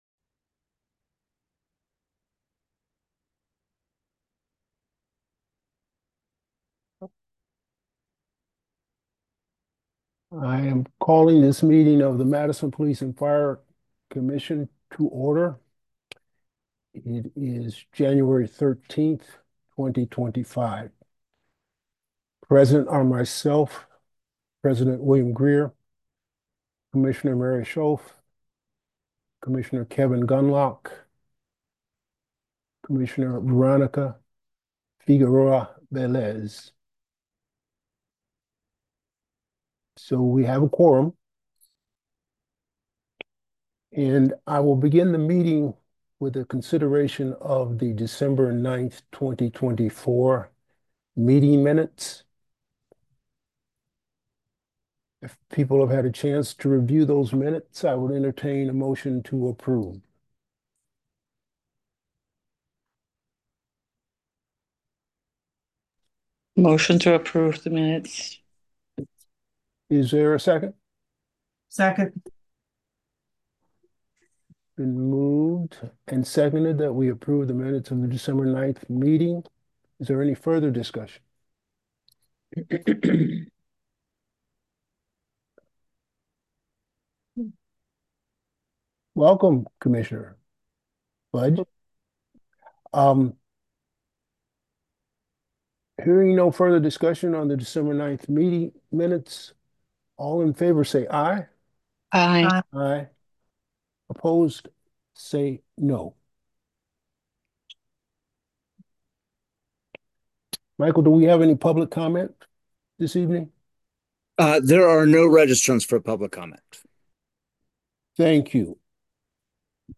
This is a podcast of Additional Meetings not normally covered by Madison City Channel.